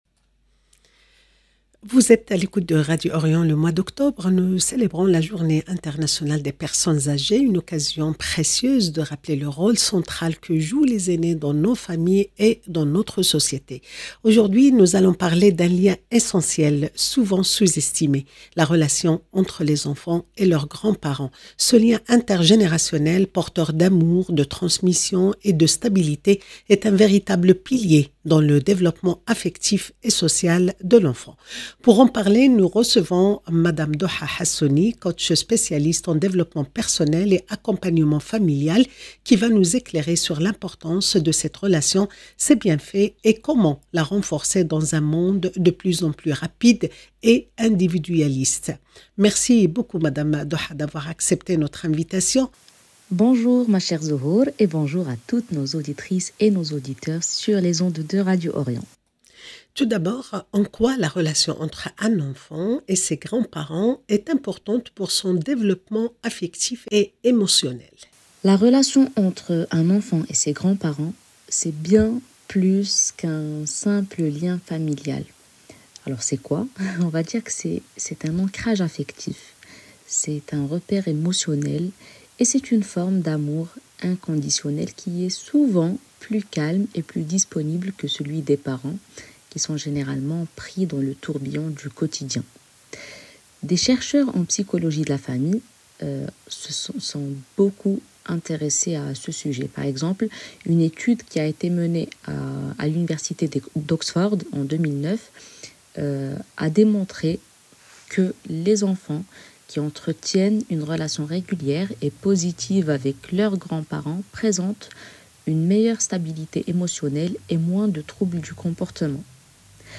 Un échange sur l’importance du lien intergénérationnel, ses bienfaits pour toute la famille, et comment cultiver cette complicité précieuse au fil du temps. 0:00 1 sec